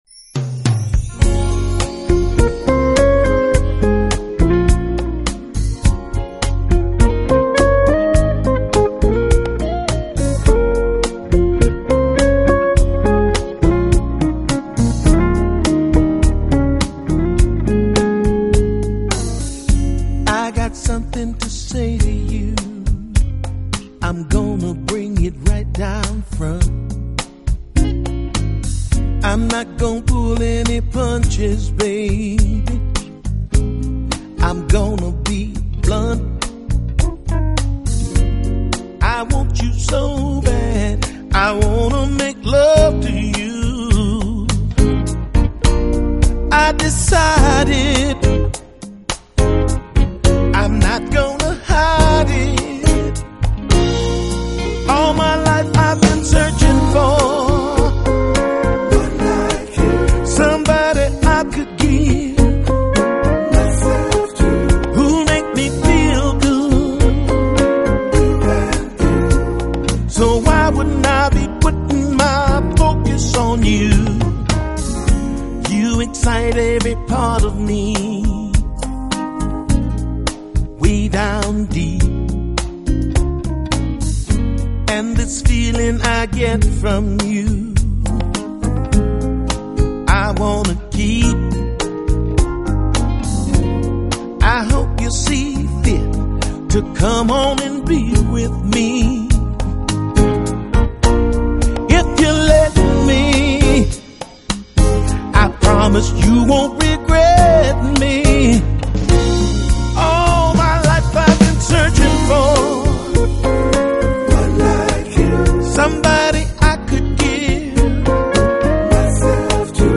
音乐风格：Smooth Jazz